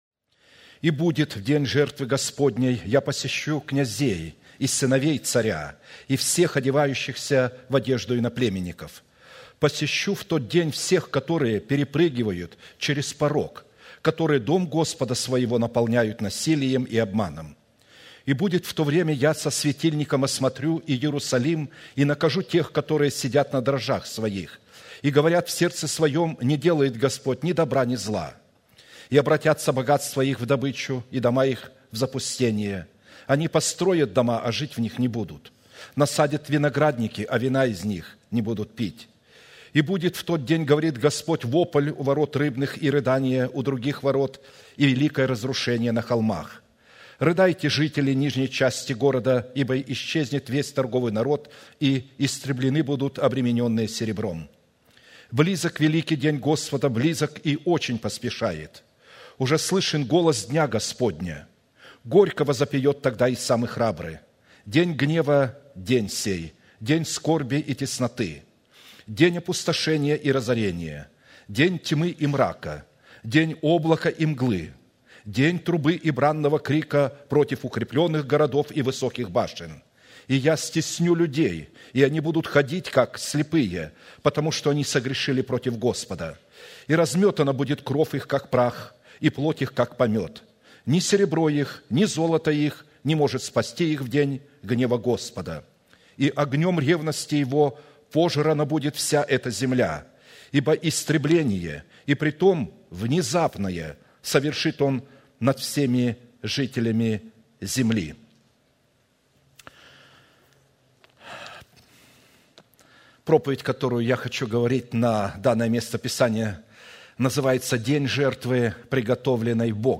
Служение: Вторник